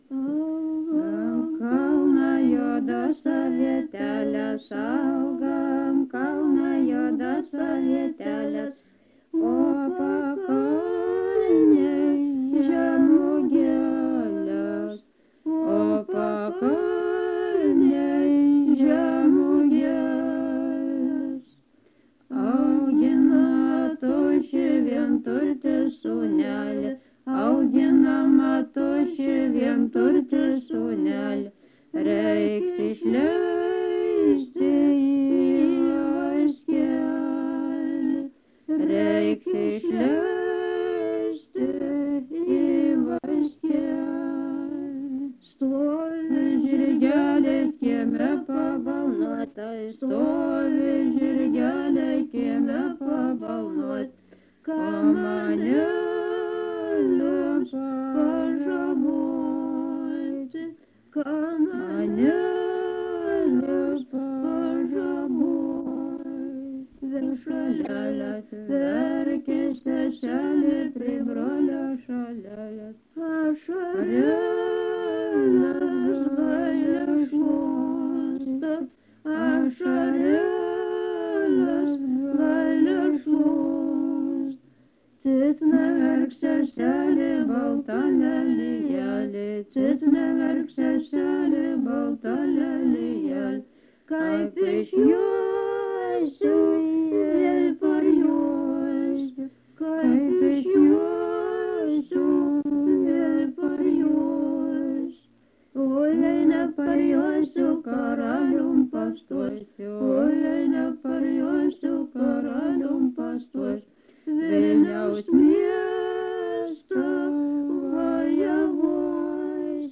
Subject daina
Erdvinė aprėptis Struikai
Atlikimo pubūdis vokalinis